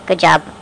Good Job Sound Effect
Download a high-quality good job sound effect.
good-job.mp3